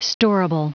Prononciation du mot storable en anglais (fichier audio)
Prononciation du mot : storable